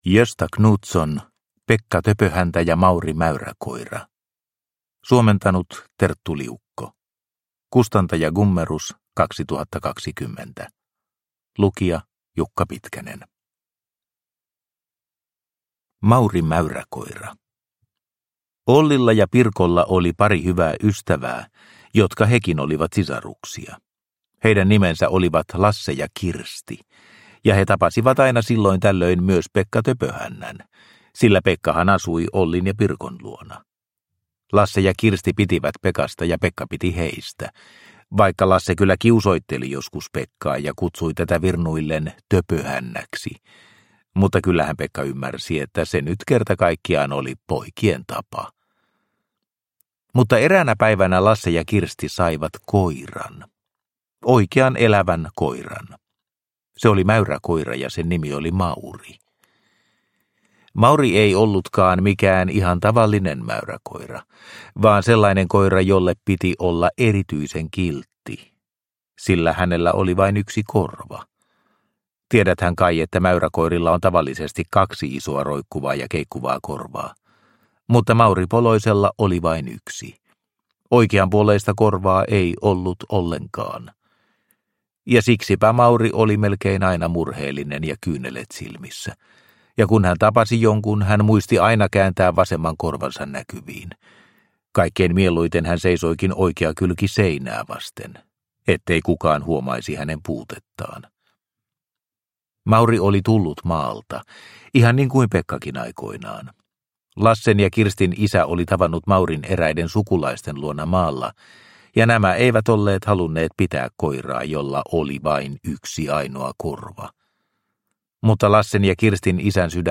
Pekka Töpöhäntä ja Mauri Mäyräkoira – Ljudbok – Laddas ner